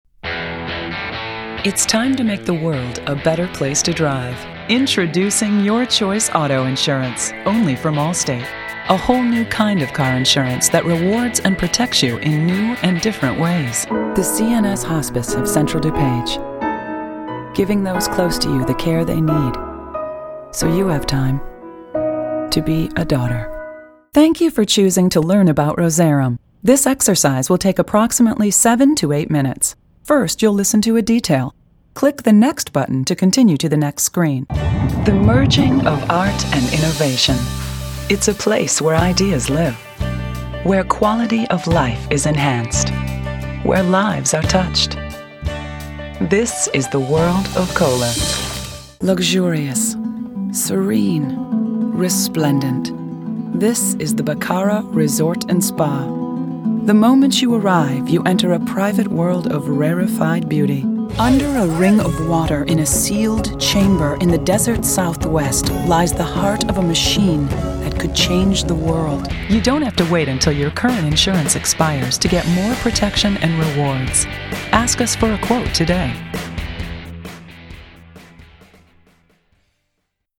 chicago : voiceover : commercial : women